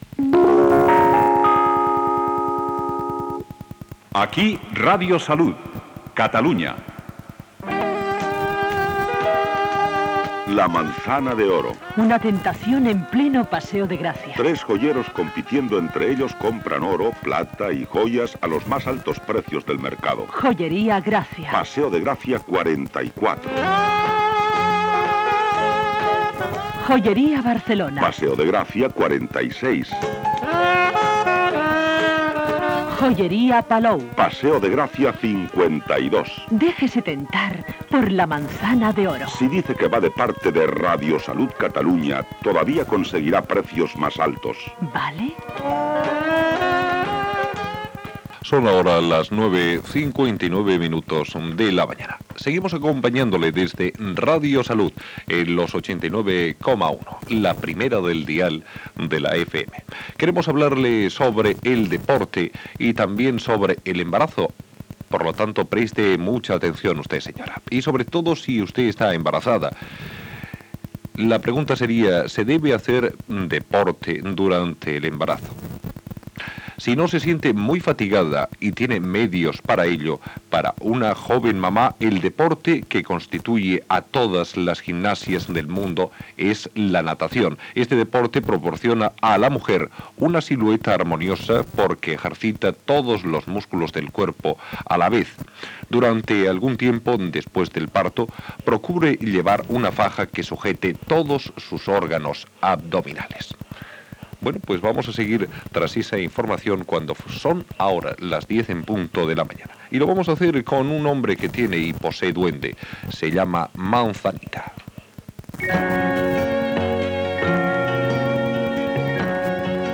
c38162f382bd325a49ba169f10c762766e1b54e4.mp3 Títol Radio Salud Emissora Radio Salud Titularitat Privada local Descripció Indicatiu (veu de Luis del Olmo), publicitat, hora i consells sobre l'esport en el moment de l'embaràs.